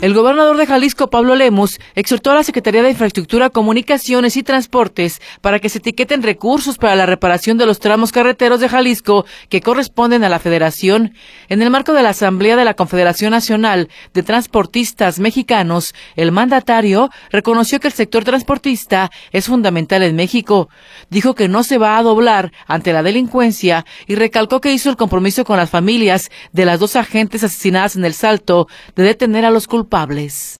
El Gobernador de Jalisco, Pablo Lemus, exhortó a la Secretaría de Infraestructura, Comunicaciones y Transporte, para que se etiqueten recursos para la reparación de los tramos carreteros de Jalisco que corresponden a la Federación. En el marco de la Asamblea de la Confederación Nacional de Transportistas Mexicanos, el mandatario reconoció que el sector transportista es fundamental en México. Dijo que no se va a doblar ante la delincuencia, y recalcó que hizo el compromiso con las familias de las dos agentes asesinadas en El Salto, de detener a los culpables.